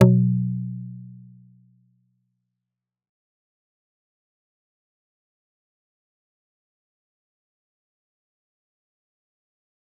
G_Kalimba-B2-f.wav